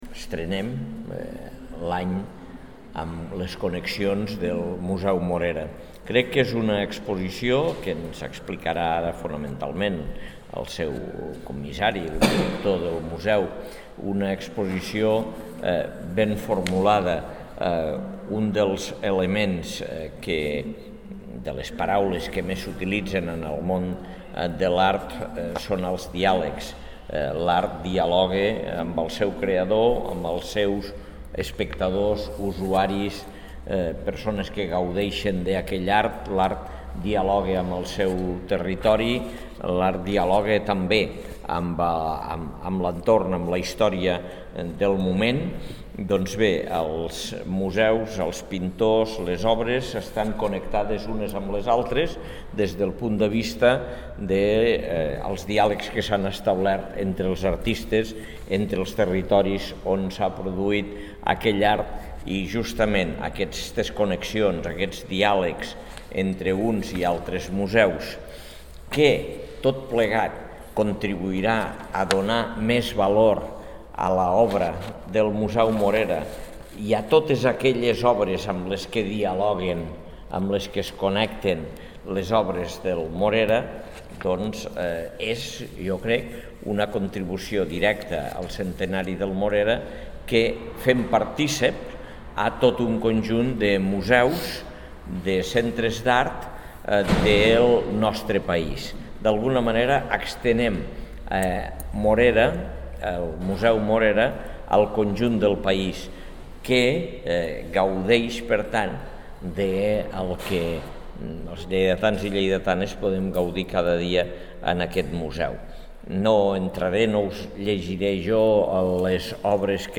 Tall de veu de l'alcalde, Àngel Ros, sobre l'exposició "Connexions" que es pot visitar fins a l'1 d'octubre al Museu d'Art Jaume Morera, en el marc del seu centenari